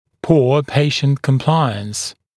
[puə ‘peɪʃnt kəm’plaɪəns] [pɔː][пуа ‘пэйшнт кэм’плайэнс] [по: ]плохое, неудовлетворительное сотрудничество пациента